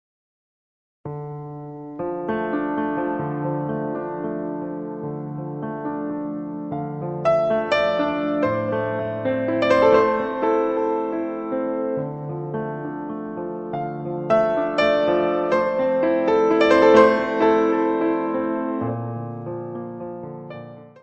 : stereo; 12 cm
Music Category/Genre:  New Musical Tendencies